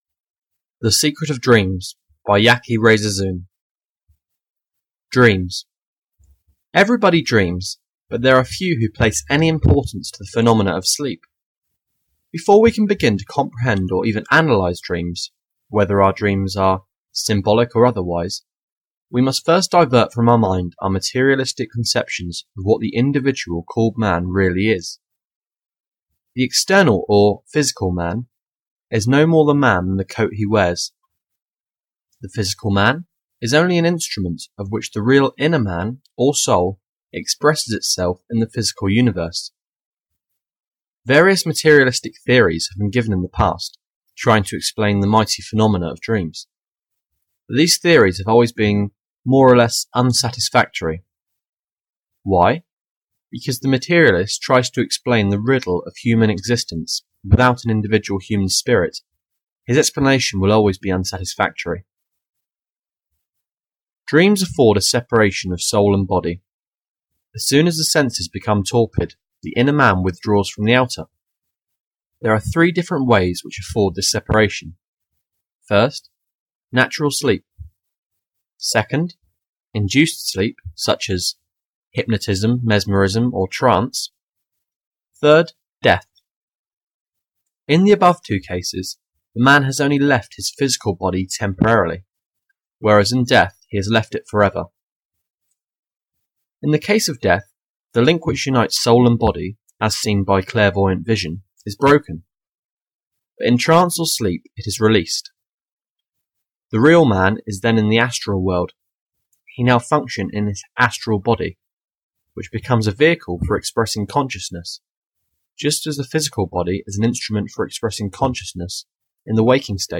Audio knihaSecret of Dreams (EN)
Ukázka z knihy